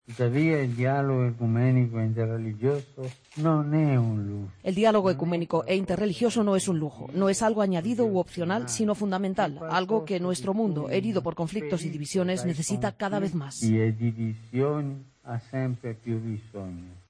Declaraciones del Papa en el encuentro que ha tenido lugar a primera hora del día en la nunciatura (encuentro interreligioso y ecuménico con los líderes de otras confesiones religiosas presentes en Kenia).